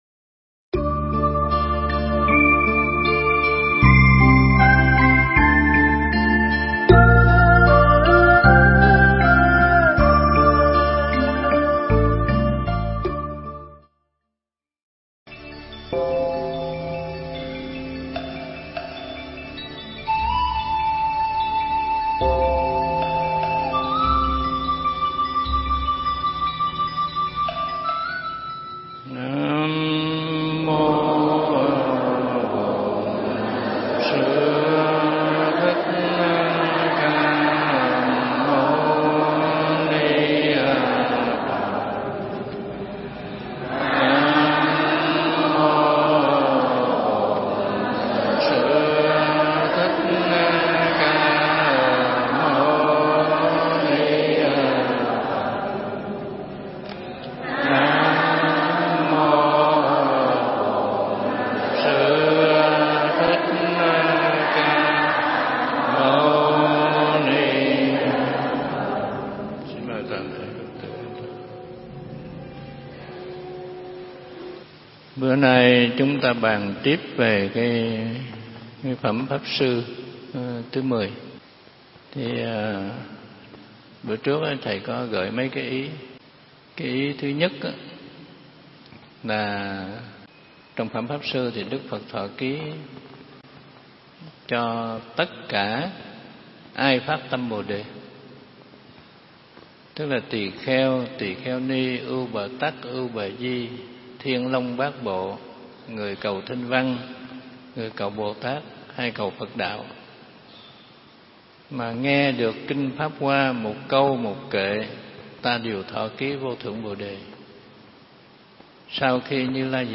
Pháp âm Bổn Môn Pháp Hoa phần 13 –Hòa Thượng Thích Trí Quảng giảng tại Chùa Huê Nghiêm, Quận 2, (ngày 7 tháng 9 năm Nhâm Thìn), ngày 21 tháng 10 năm 2012